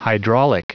Prononciation du mot hydraulic en anglais (fichier audio)
Prononciation du mot : hydraulic